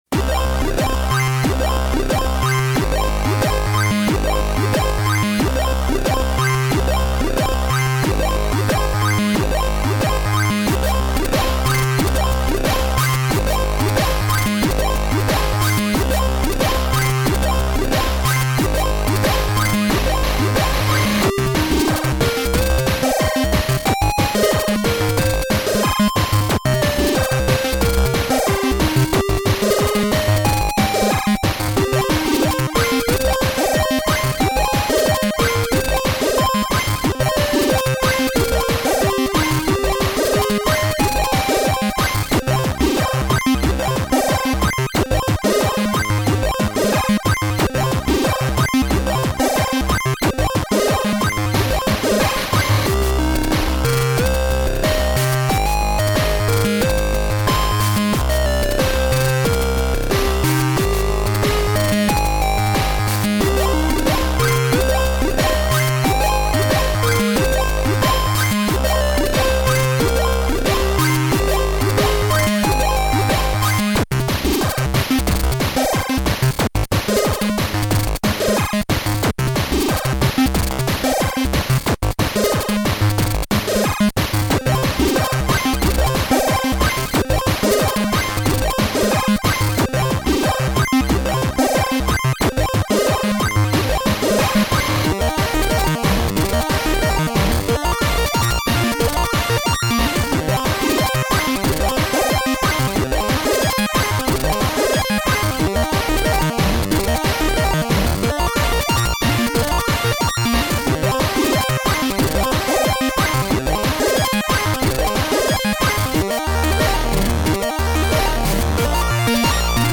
ohne Musik